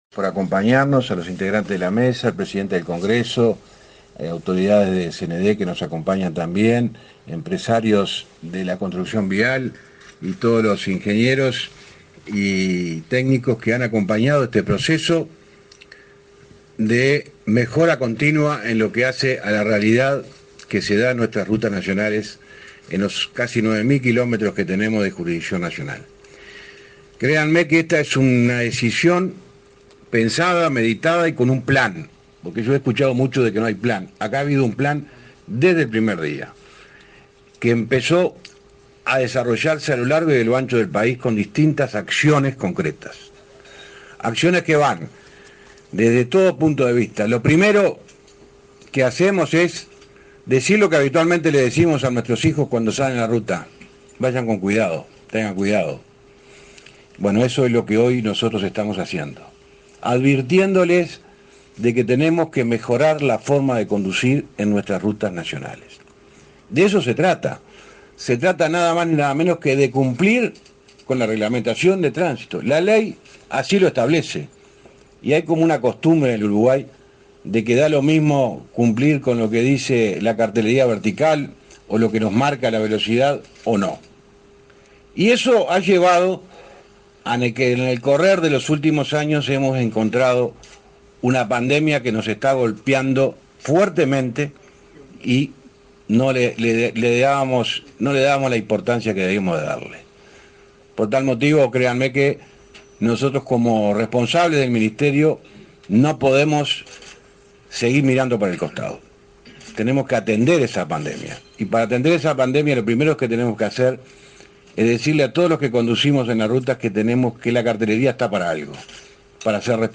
Conferencia de prensa por el lanzamiento del Centro de Sistemas Inteligentes de Tránsito
Conferencia de prensa por el lanzamiento del Centro de Sistemas Inteligentes de Tránsito 07/08/2023 Compartir Facebook X Copiar enlace WhatsApp LinkedIn Con la presencia del ministro de Transporte y Obras Públicas, José Luis Falero, y del presidente de la Unasev, Alejandro Draper, se realizó el lanzamiento del Centro de Sistemas Inteligentes de Tránsito.